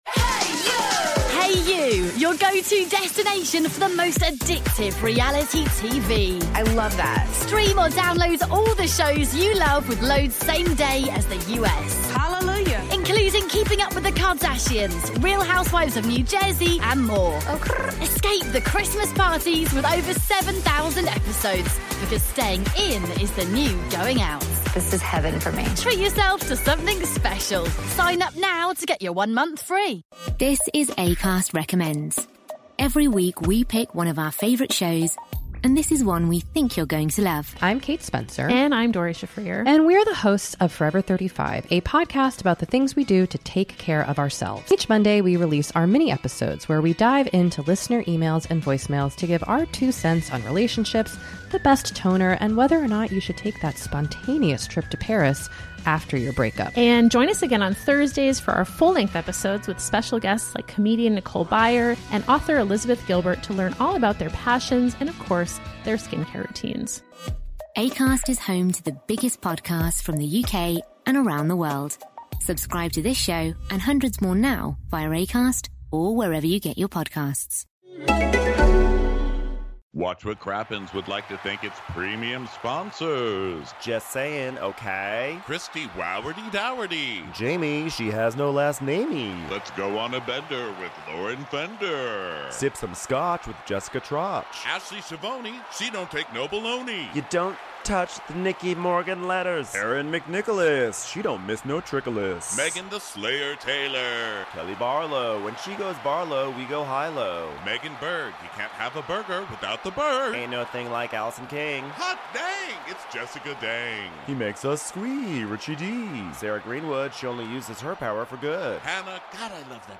We're live from The Oriental Theater in Denver for this very special episode of Real Housewives of Orange County.